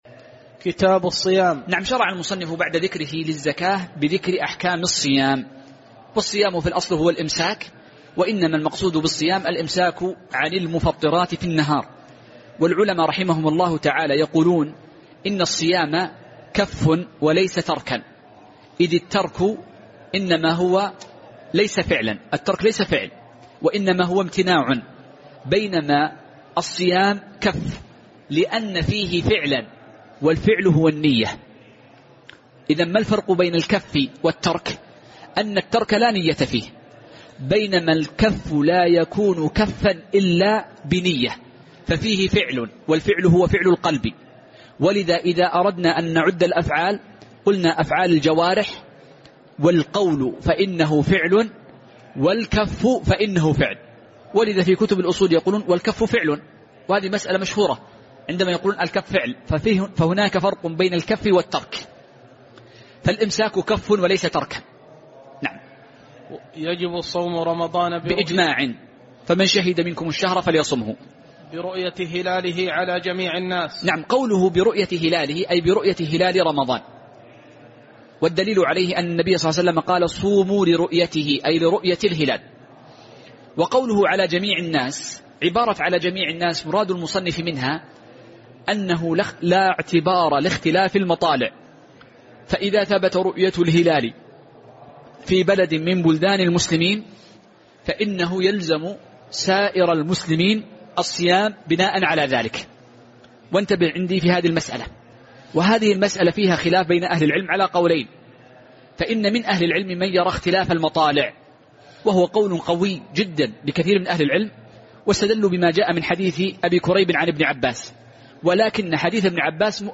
تاريخ النشر ١٣ جمادى الأولى ١٤٤١ هـ المكان: المسجد النبوي الشيخ